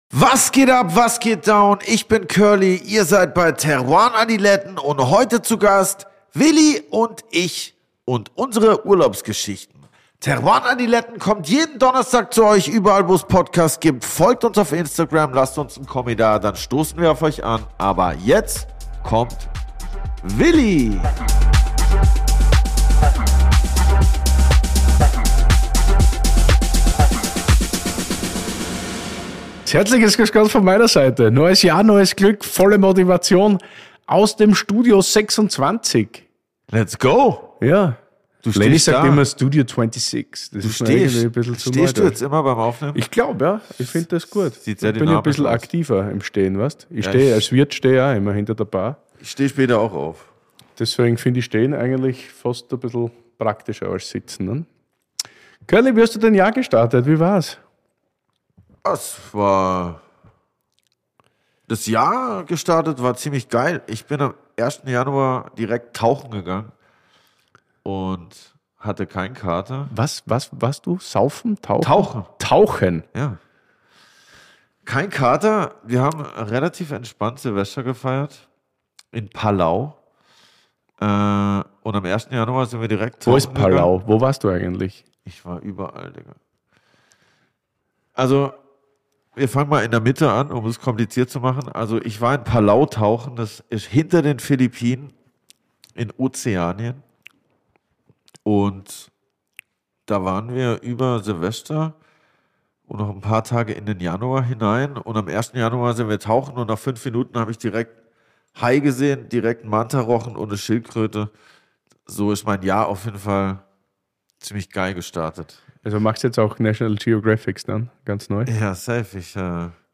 live im Burgenland!
im sagenumwobenen Weingut Moric.